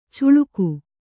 ♪ cuḷuku